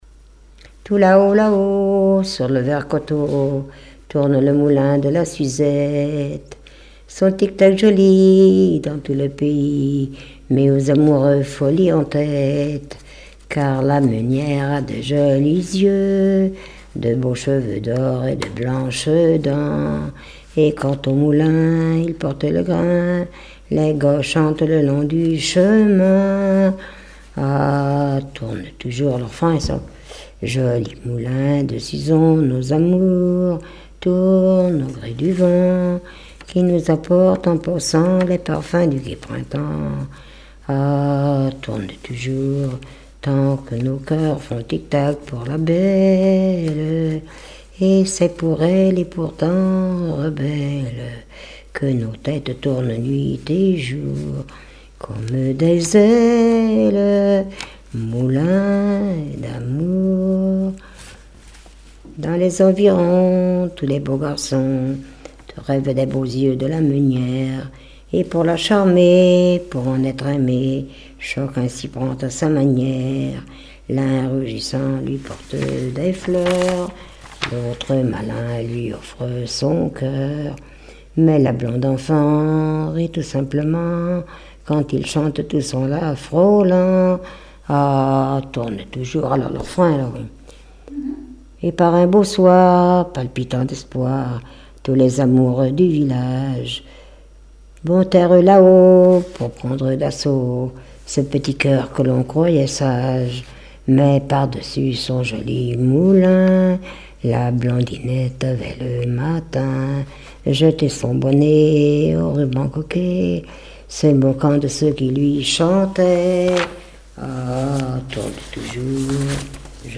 Genre strophique
Répertoire de chansons populaires et traditionnelles
Pièce musicale inédite